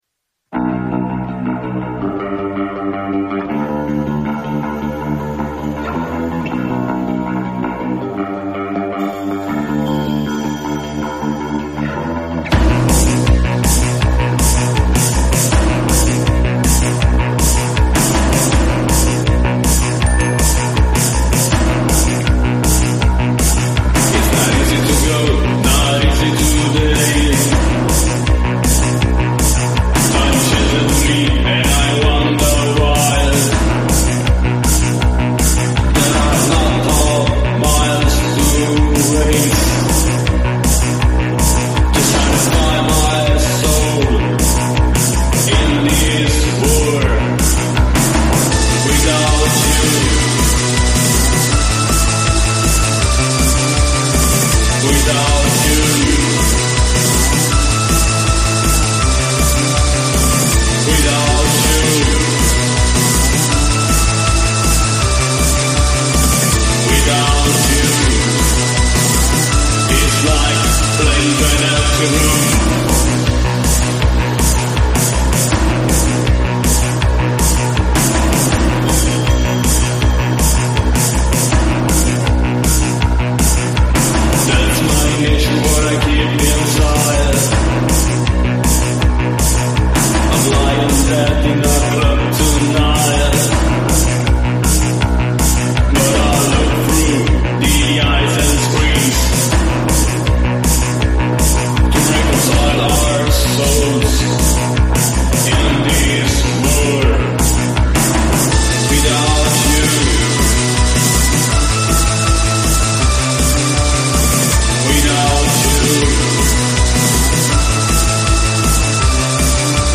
DarkPop